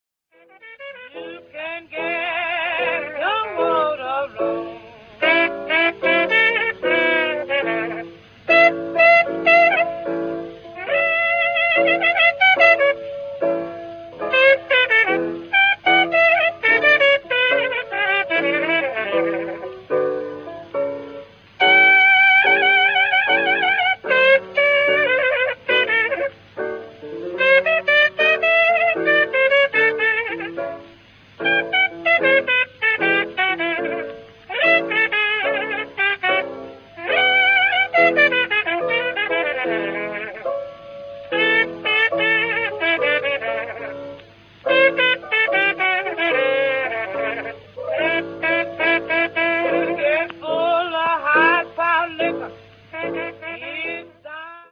New York, 8 gennaio 1925